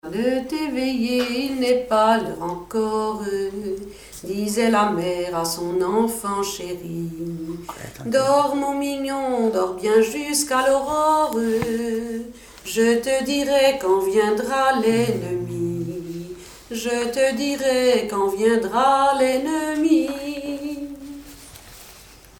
enfantine : berceuse
Genre strophique
Chansons et commentaires
Pièce musicale inédite